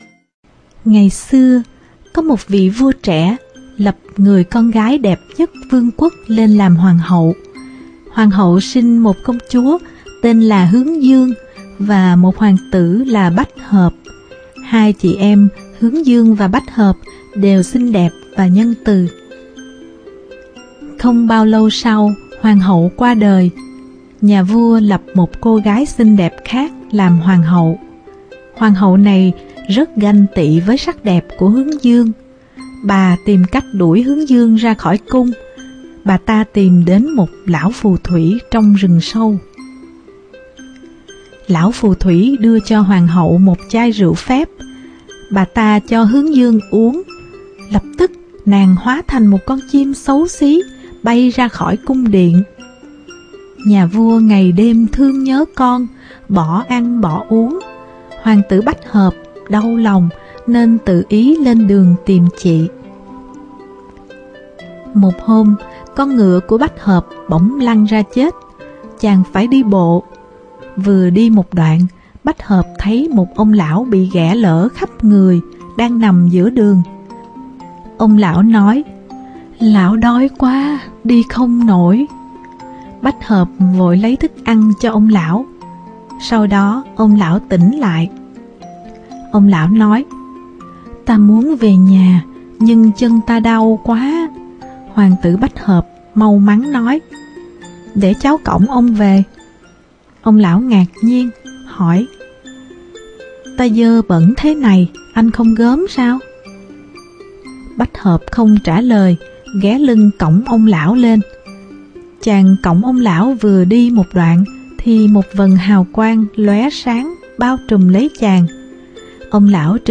Sách nói | NÀNG CÔNG CHÚA HÓA CHIM